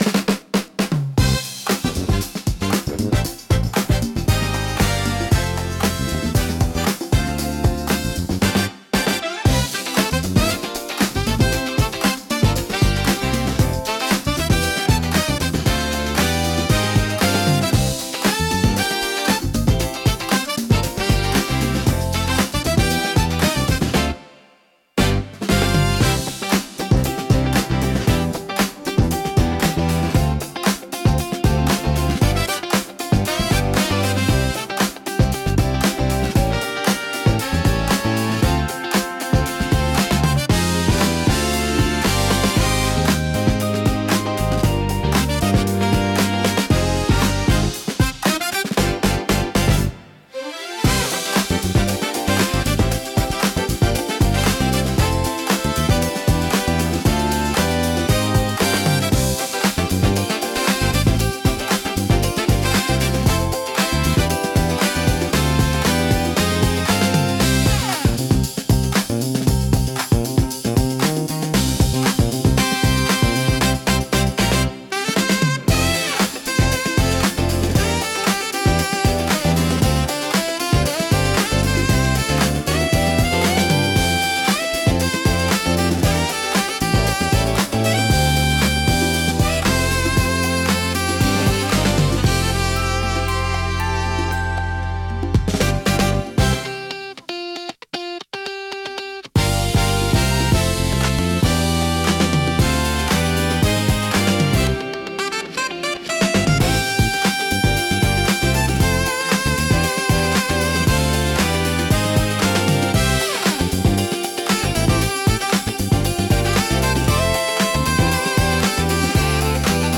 心地よいリズムが聴く人を踊らせ、楽しい雰囲気を盛り上げる効果があります。躍動感と活気に満ちたジャンルです。